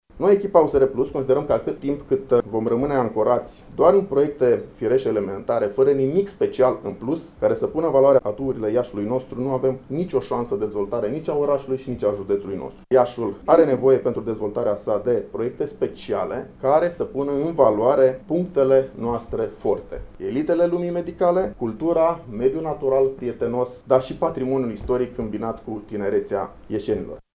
1 sept 2020 – Ştiri electorale ora 18:00